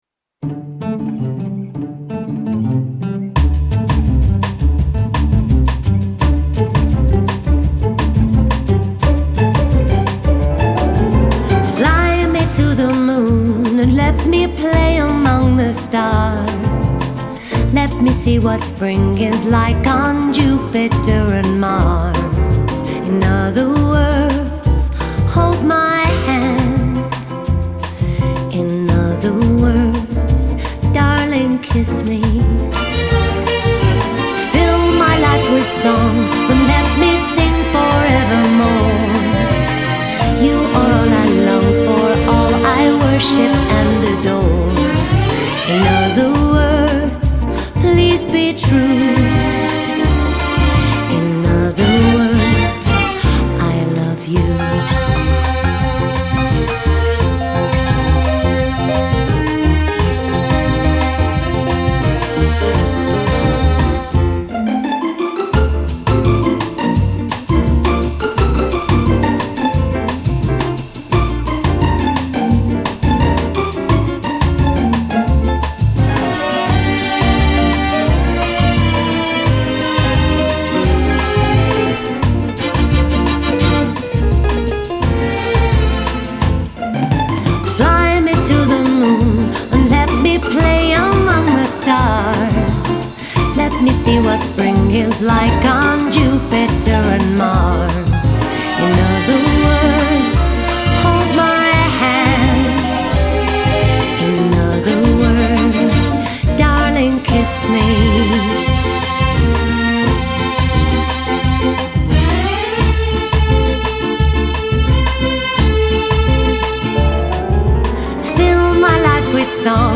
Fantastic female Duo with a great jazz sound.